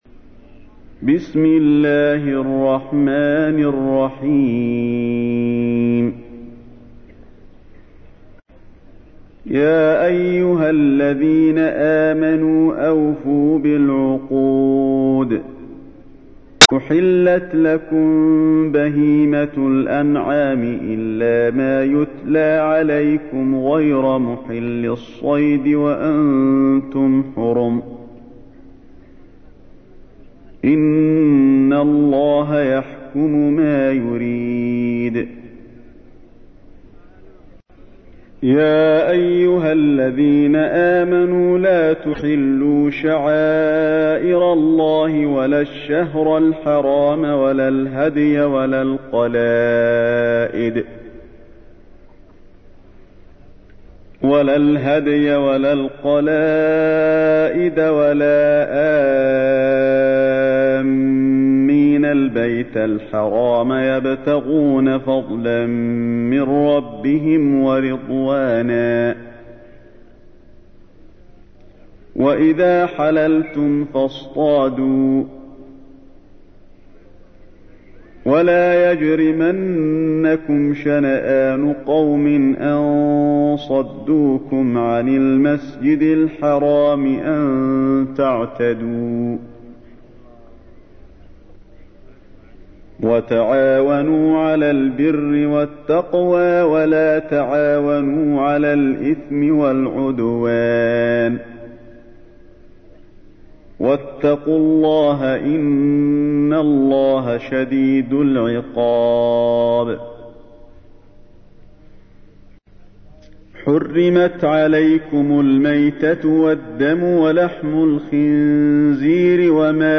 تحميل : 5. سورة المائدة / القارئ علي الحذيفي / القرآن الكريم / موقع يا حسين